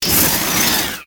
Butobasu_Cry.ogg